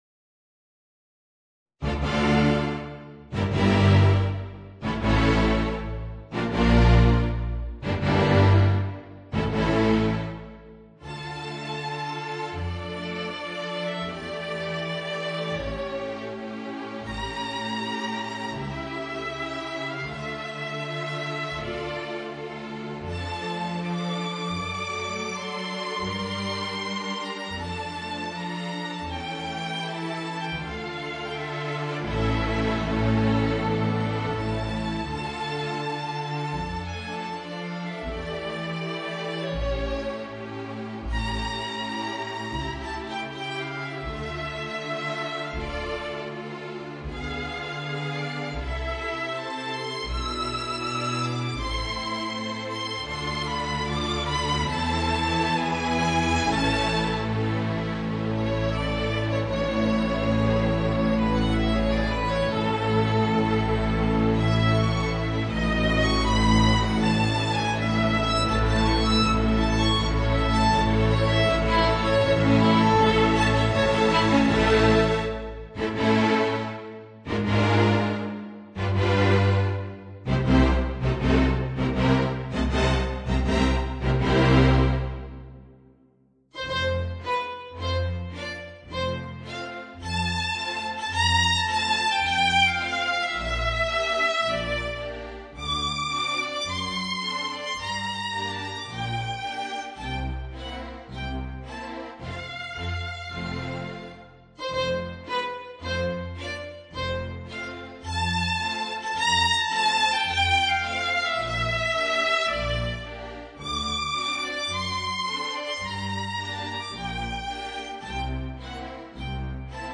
Voicing: Violin and String Orchestra